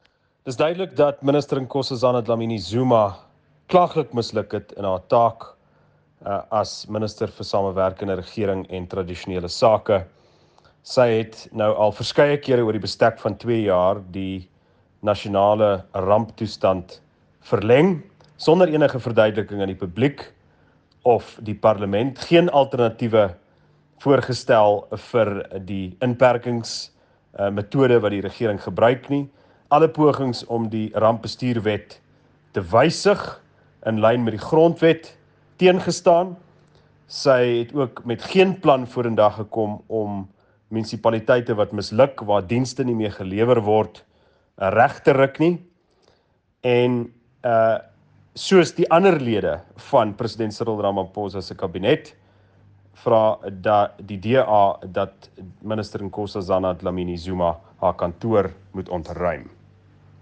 Afrikaans soundbites by Cilliers Brink MP.